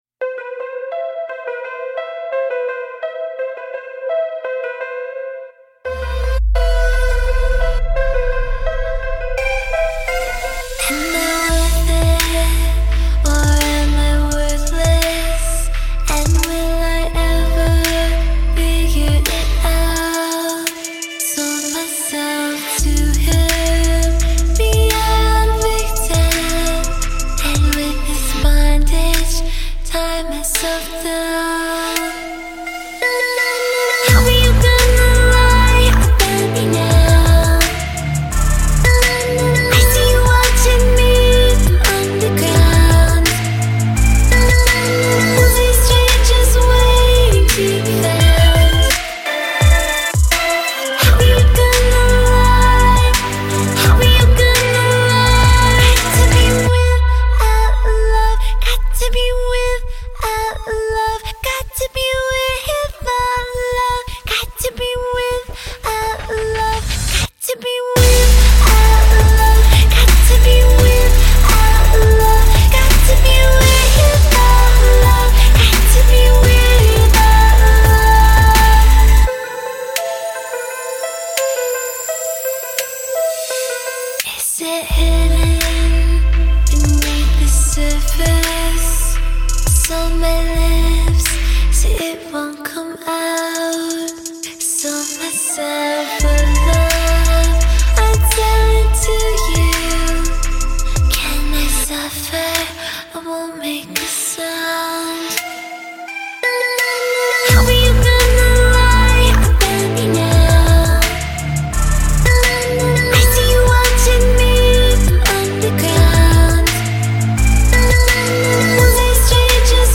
# Electronic